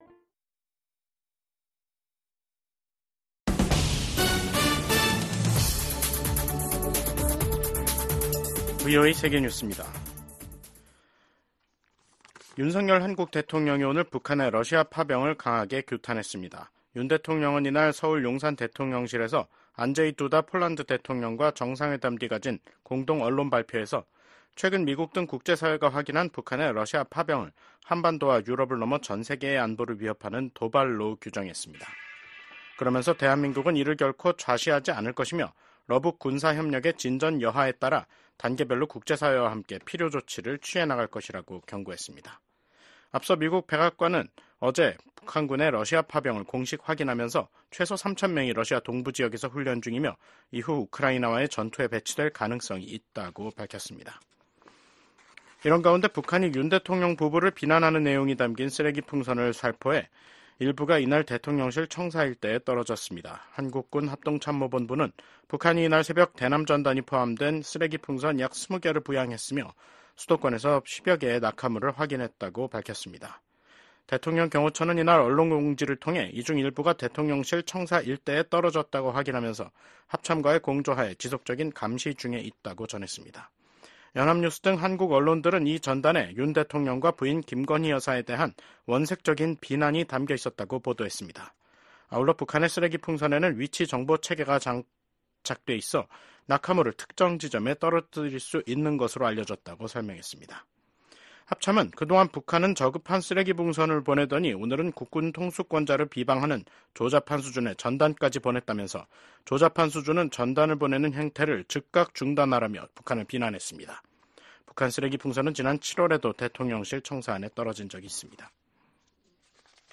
VOA 한국어 간판 뉴스 프로그램 '뉴스 투데이', 2024년 10월 24일 2부 방송입니다. 미국 백악관이 북한군의 러시아 파병을 공식 확인했습니다. 최소 3천명이 러시아 동부 전선에 파병됐으며 훈련 뒤엔 우크라이나와의 전투에 배치될 가능성이 있다고 밝혔습니다.